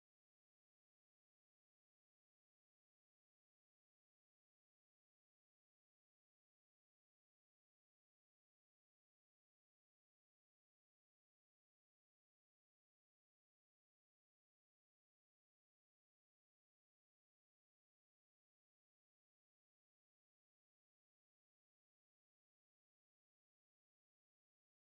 BMW 740Li B58 Engine sound effects free download
BMW 740Li B58 - Engine Mp3 Sound Effect